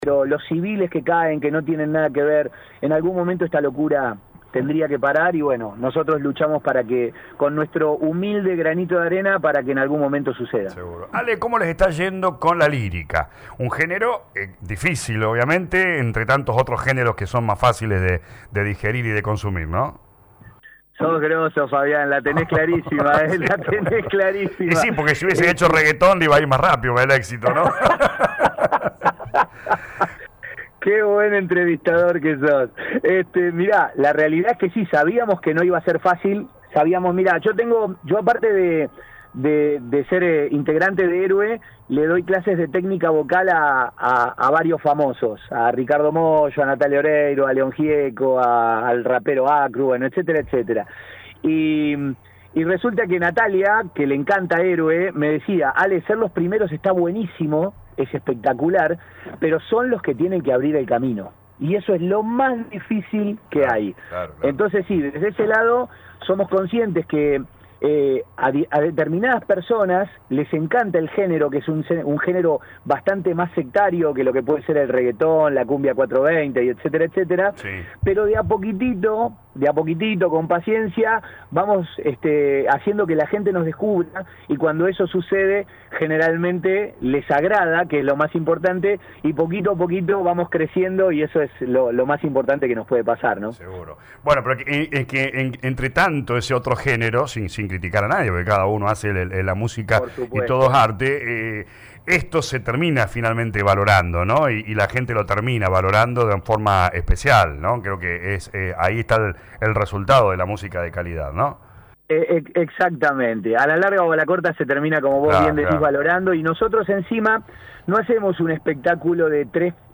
Compartimos «Desarma y Sangra»  un homenaje realizado en Puerto Argentino, Islas Malvinas, por el grupo HÉROE junto a 45 argentinos (entre ellos 25 excombatientes), en reconocimiento a la tremenda gesta llevada a cabo en el conflicto bélico de 1982 y como reivindicación al inexplicable olvido al que fueron confinados después.